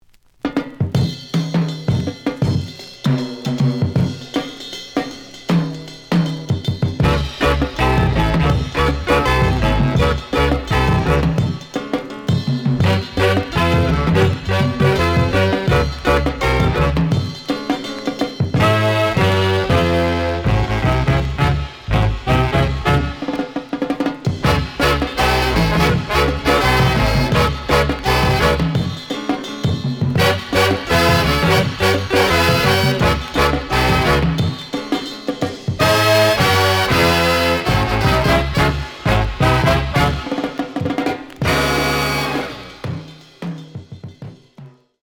The audio sample is recorded from the actual item.
●Genre: Latin